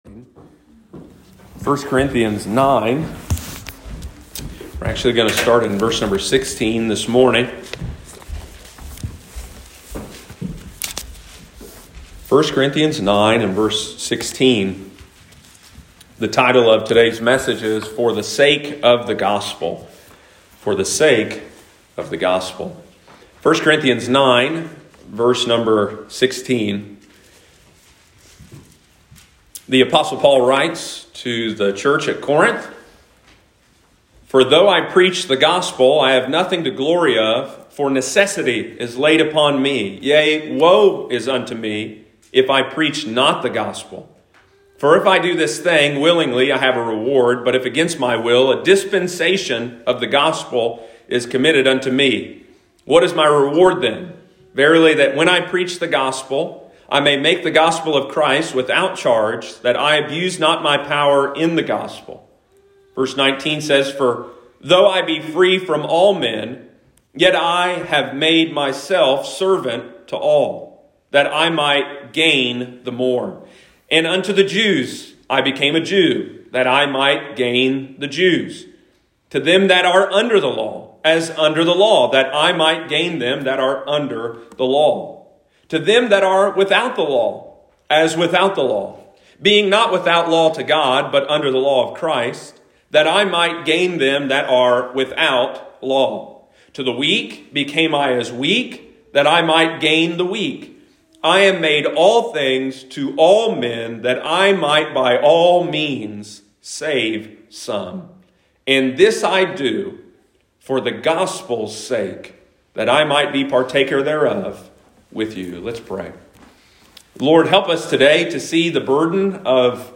Do we live our lives with a constant focus on the Gospel? The apostle Paul proved that everything he did was for the sake of the Gospel! First Corinthians series, Sunday morning, May 9, 2021.